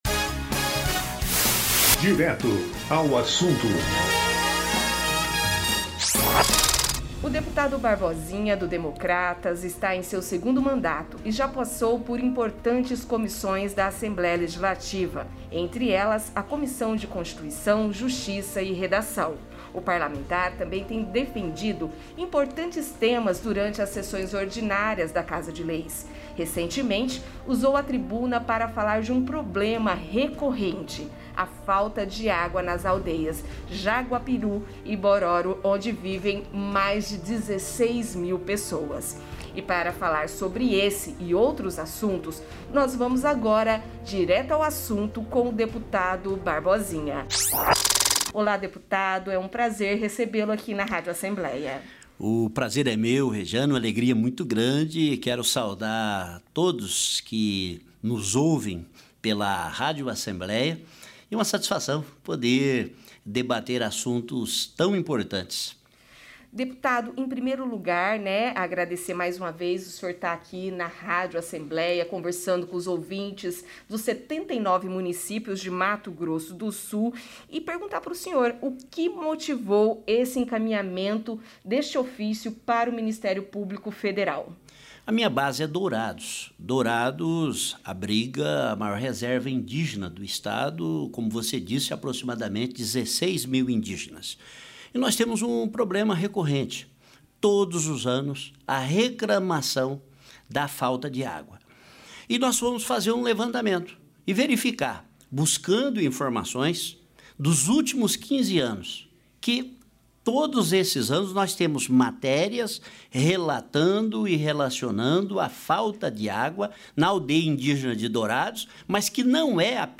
O programa Direto ao Assunto da Rádio ALEMS, que vai ao ar nesta sexta-feira (1º), conta com a participação do deputado Barbosinha (DEM), que está em seu segundo mandato, e já passou por importantes comissões da Assembleia Legislativa, entre elas a Comissão de Constituição Justiça e Redação. O parlamentar também tem defendido importantes temas durante as sessões ordinárias da Casa de Leis.